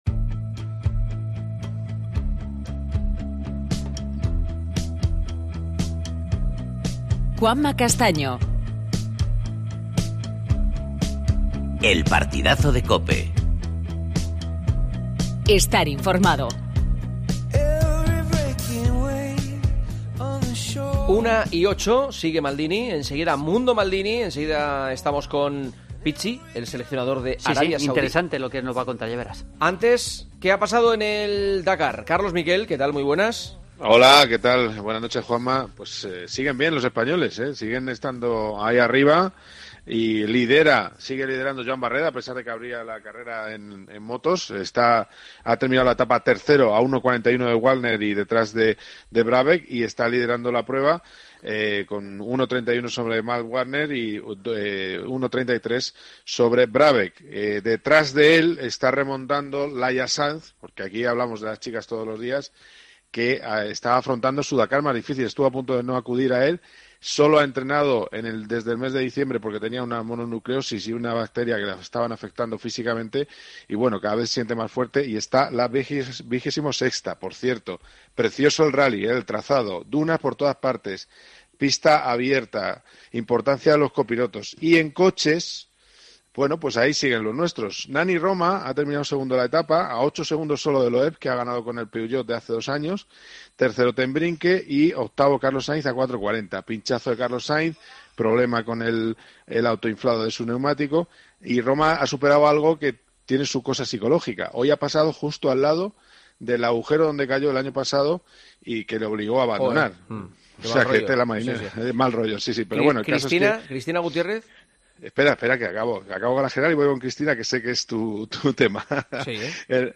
Entrevista a Juan Antonio Pizzi, en Mundo Maldini.